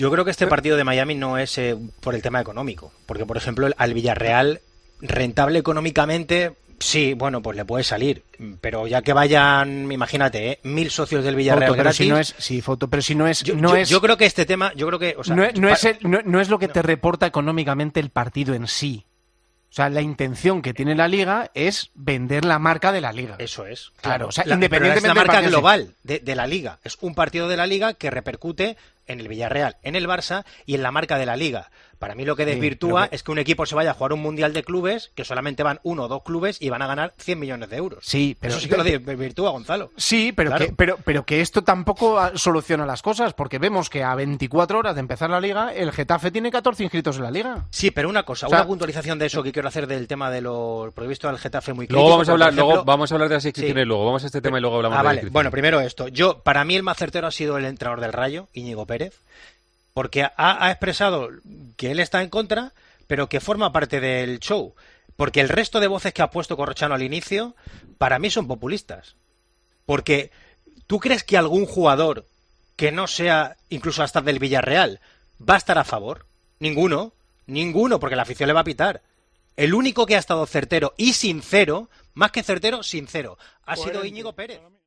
Debat sobre les opinions que ha suscitat la proposta que el partit de la lliga masuclina de futbol professional Villarreal-Futbol Club Barcelona es jugui a Miami
Esportiu